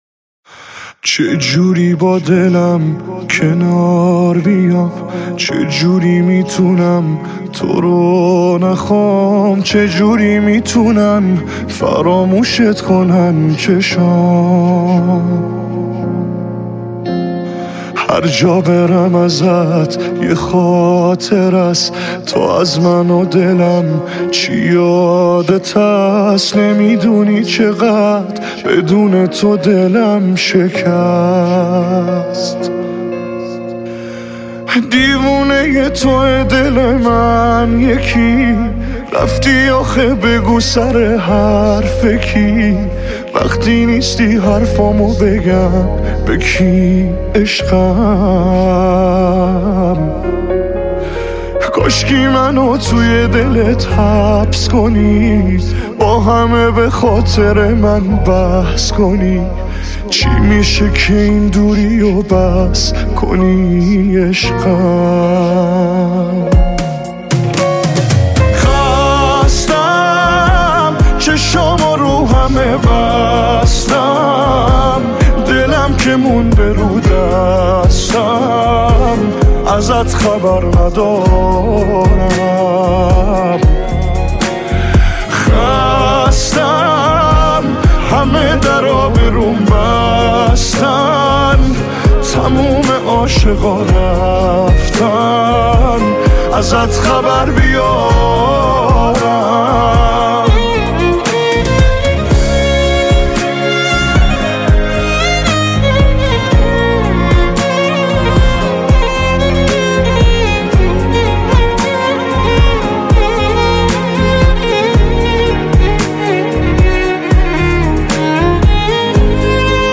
سبک : موسیقی پاپ